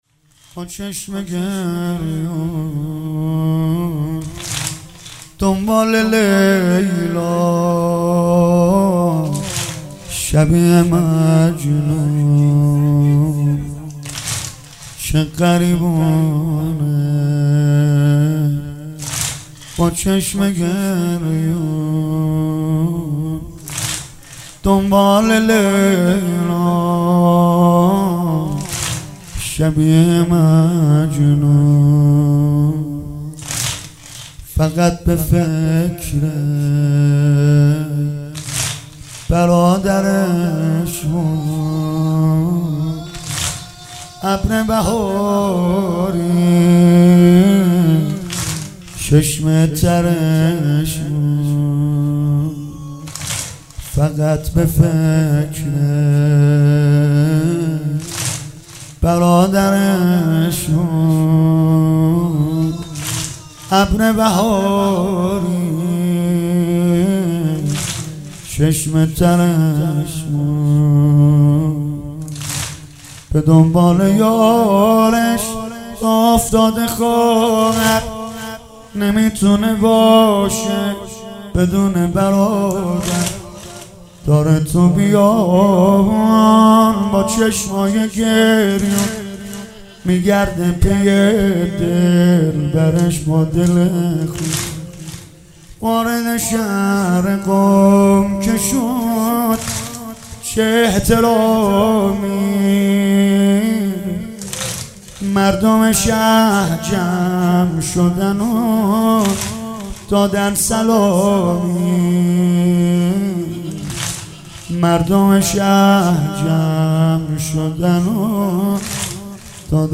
گلچین مداحی های شهادت حضرت معصومه(س)